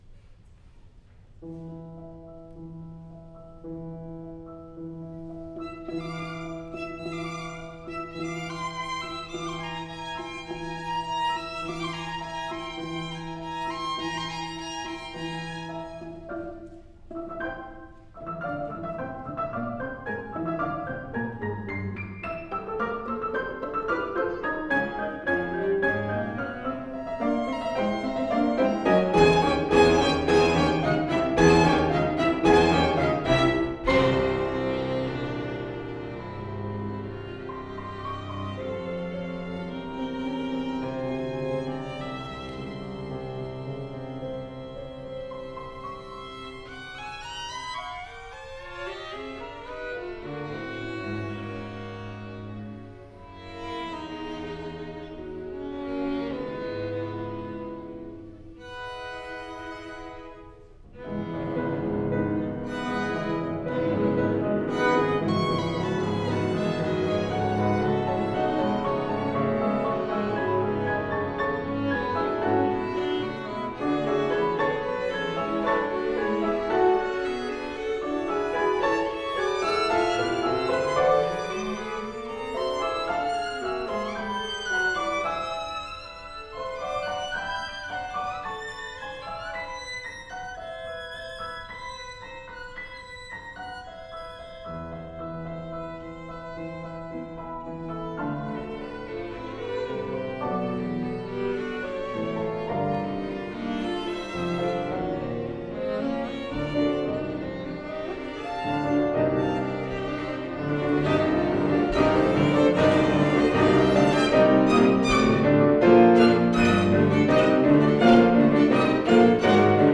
Recording is from Feb. 18 2014 concert of the piano quintet version.
violins
viola
cello